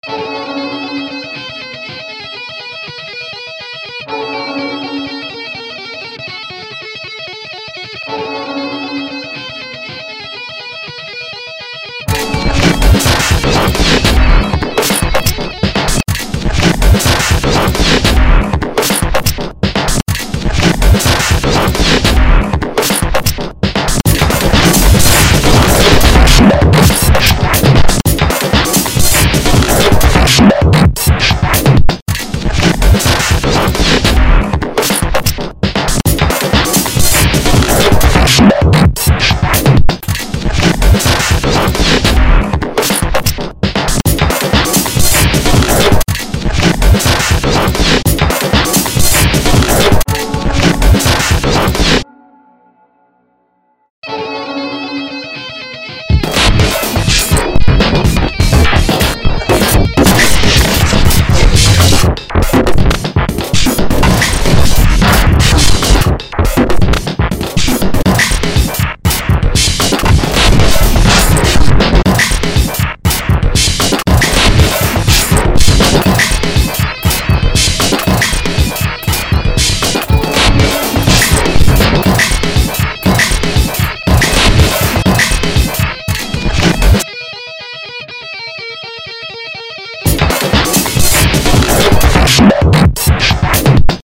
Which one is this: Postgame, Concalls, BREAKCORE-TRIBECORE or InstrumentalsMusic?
BREAKCORE-TRIBECORE